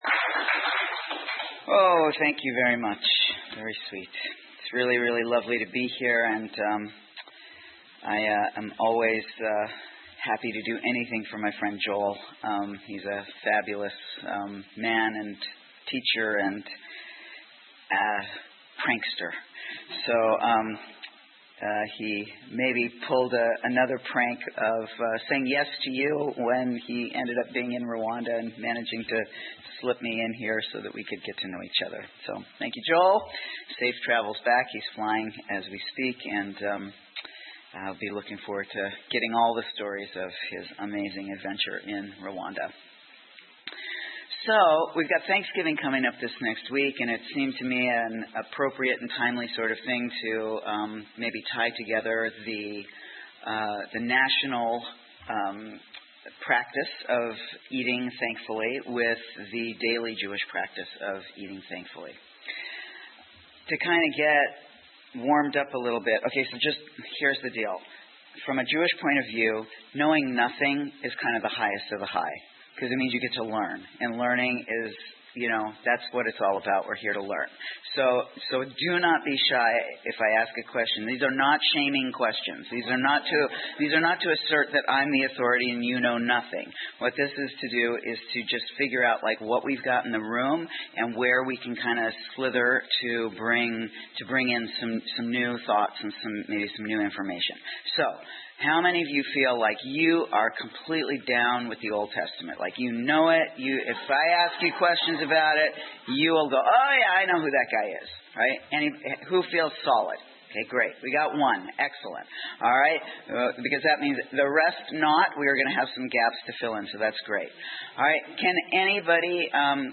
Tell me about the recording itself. Fireside Room 1 Lawson Road, Kensington, CA